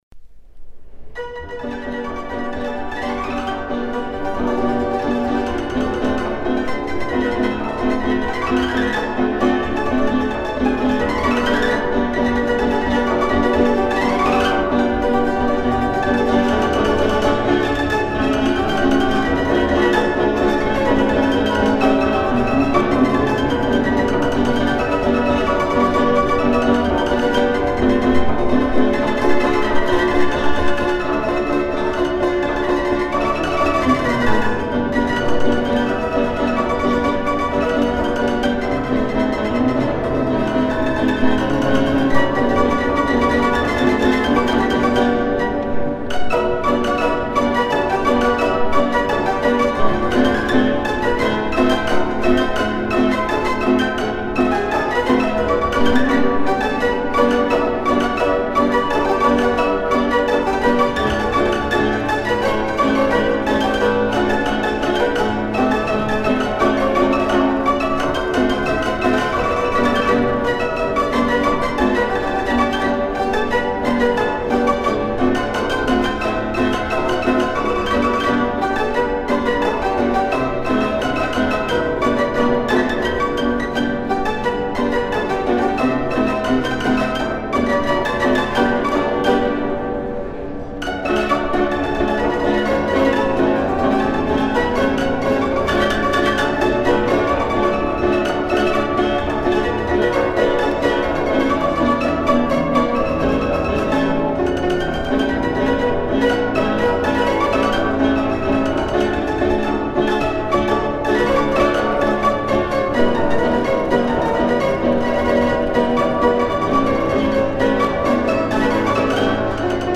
piano mécanique Valse, scottisch et polka jouées au piano mécanique